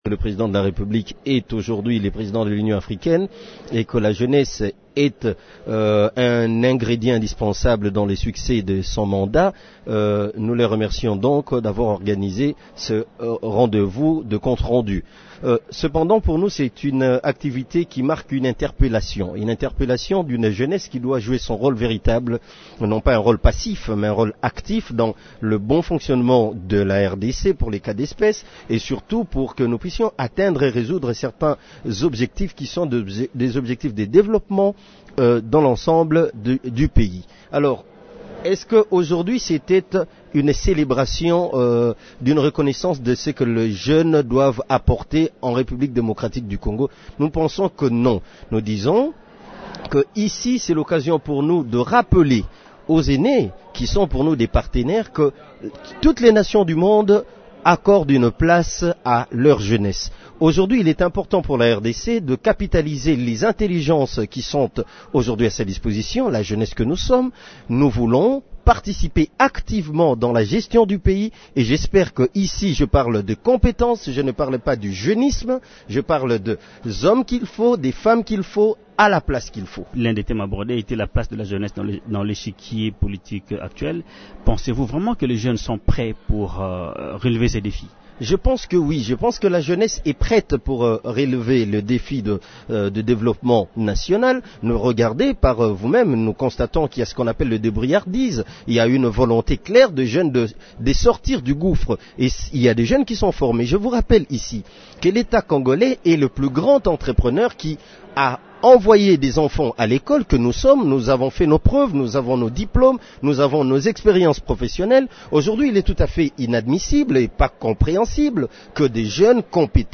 « Il est incompréhensible, inadmissible que des jeunes compétents soient toujours mis sur la touche », se désole-t-il, lors de la séance de restitution du 34e session de l’Union africaine, organisée par le Forum national de la jeunesse (FNJ).